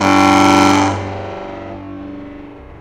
alarm